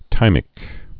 (tīmĭk, thī-)